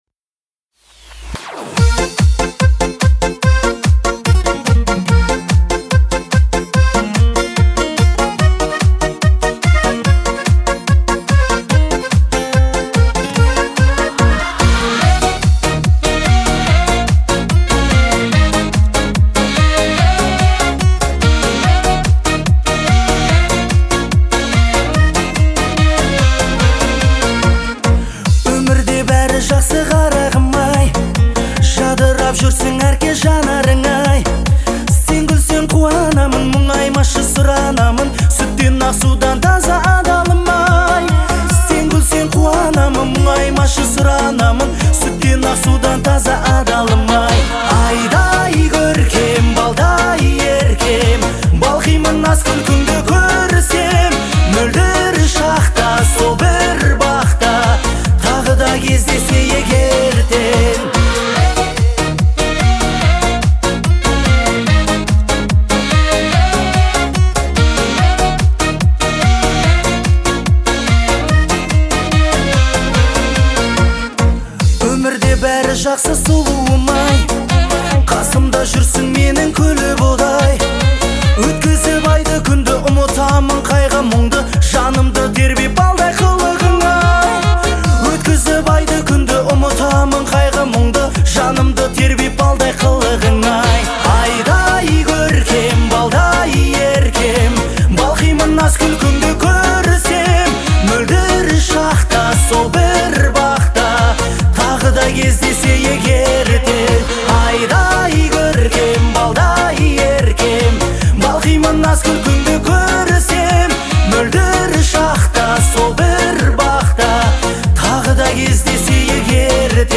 это романтичная и мелодичная песня в жанре казахского попа